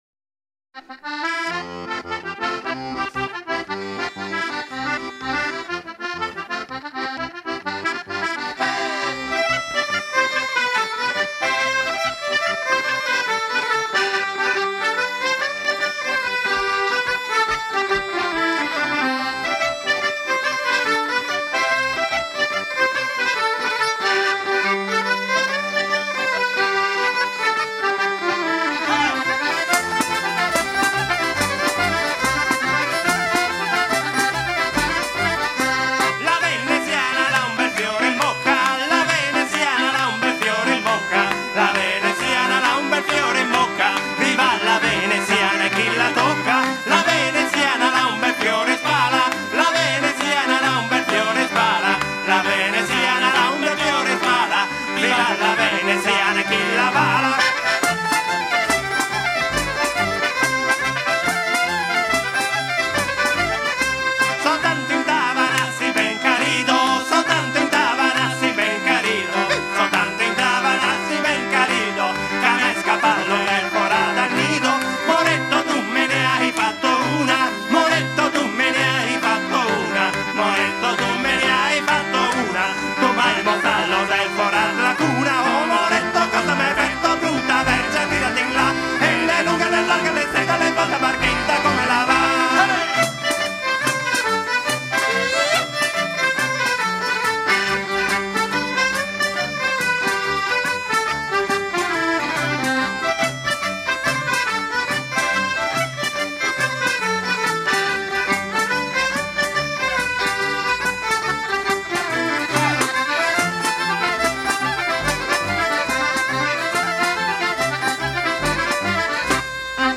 chants à danser la vilote de la région de Venise
danse : vilote (Italie)